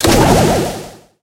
sprout_atk_ulti_01.ogg